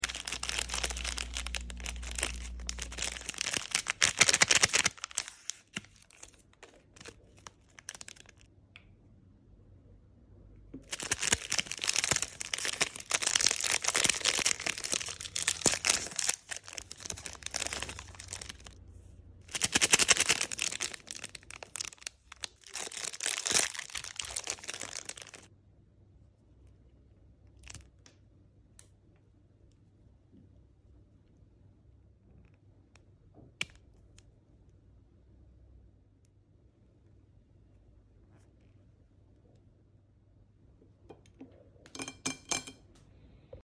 Asmr Filling Platter Nesquik snack sound effects free download
Asmr Filling Platter Nesquik snack , milka choco snack , Oreo Fresh milk snack